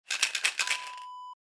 Pill container sound effect .wav #3
Description: The sound of a pill container being shaken
Properties: 48.000 kHz 24-bit Stereo
A beep sound is embedded in the audio preview file but it is not present in the high resolution downloadable wav file.
Keywords: plastic, pills, rattle, shake, shaking
pill-container-preview-3.mp3